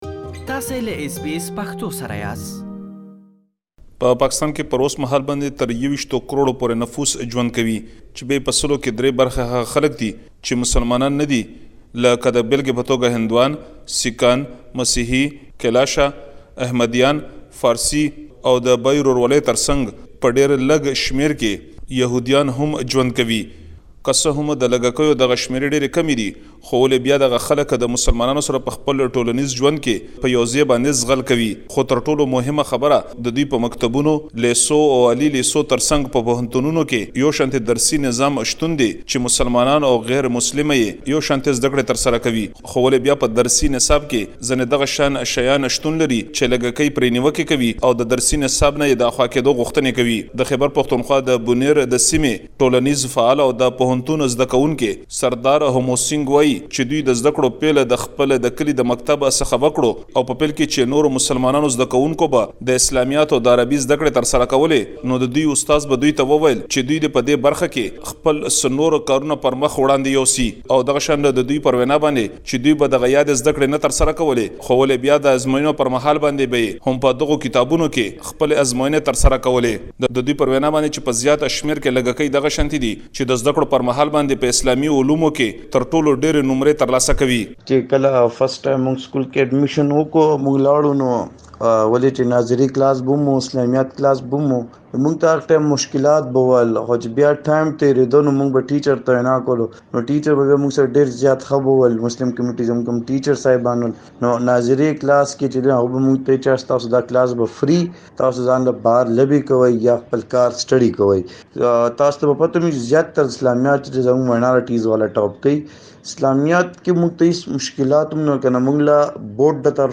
پاکستان کې د لږکيو خبرې راخيستي چې دلته يې اوريدلی شئ.